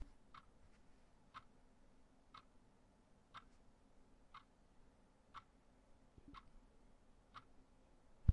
小钟
描述：由Zoom H1记录